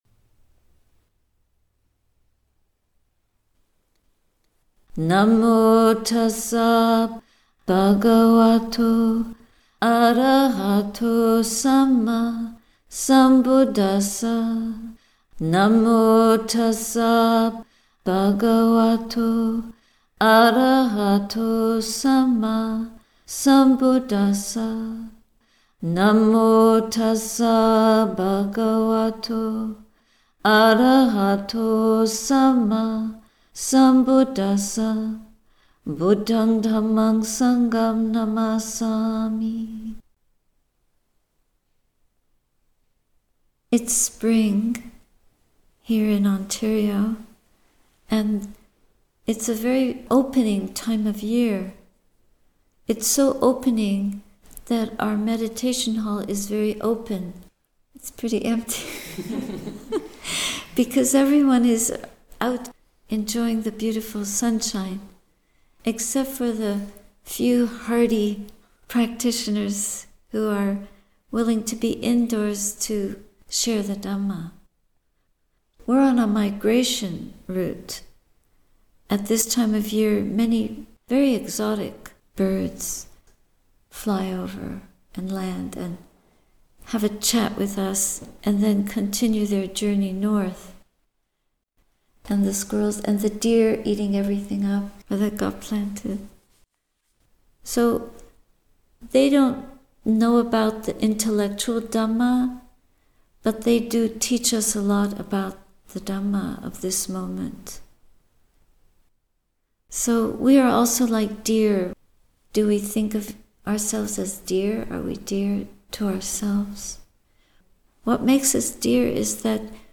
A talk given at Sati Saraniya Hermitage, April 13, 2025 https